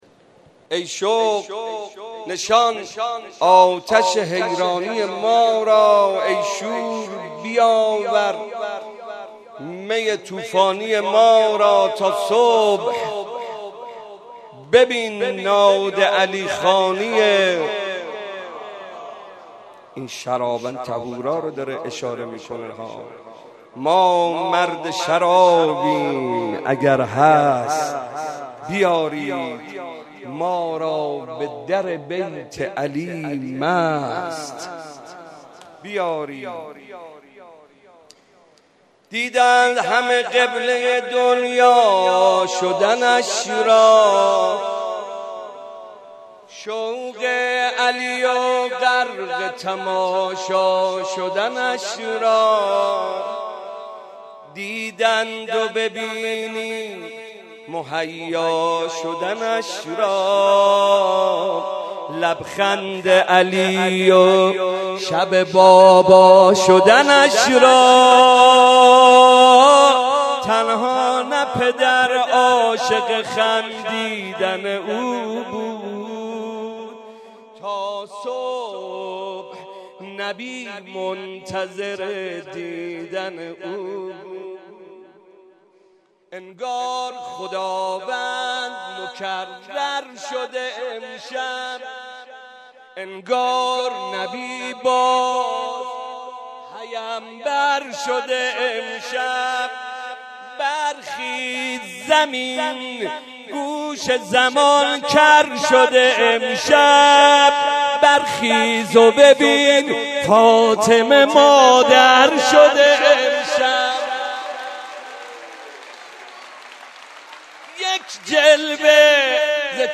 05.madh khani.mp3